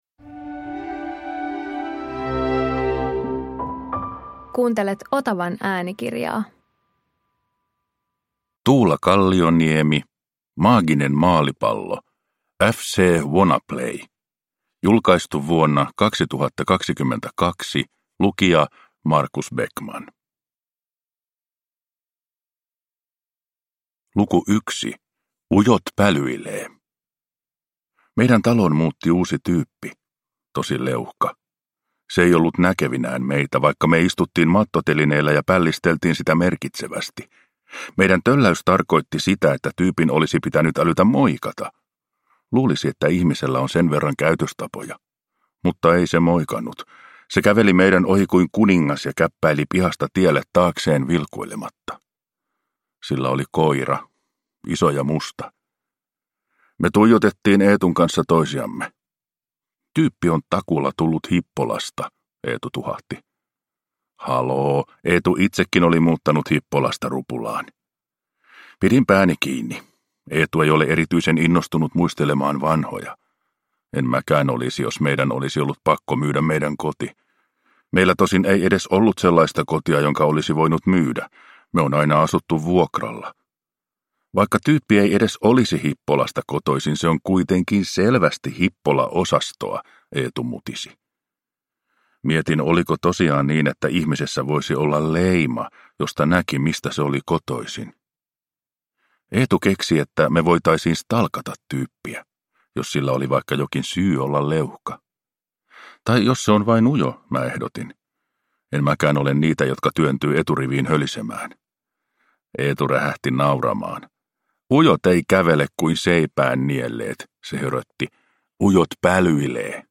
Maaginen maalipallo – Ljudbok – Laddas ner